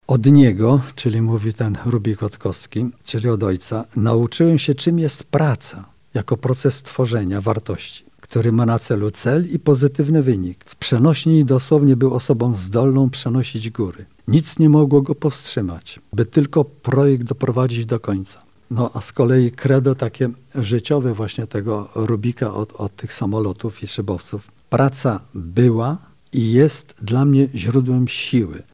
Lublin: Spotkanie autorskie wokół książki o rodzinie Rubików